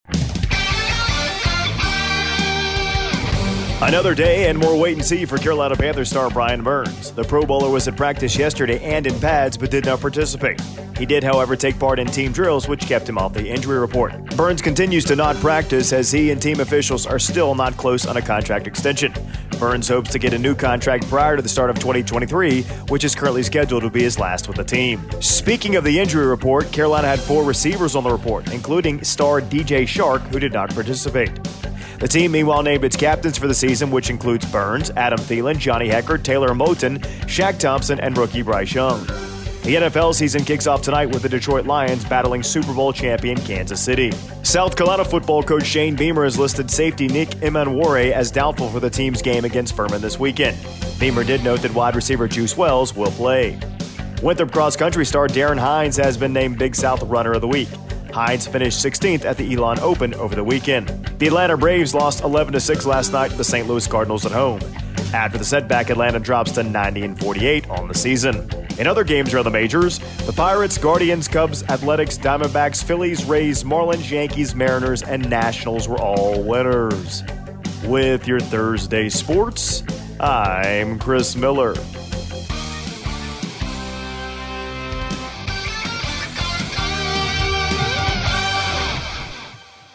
AUDIO: Thursday Morning Sports Report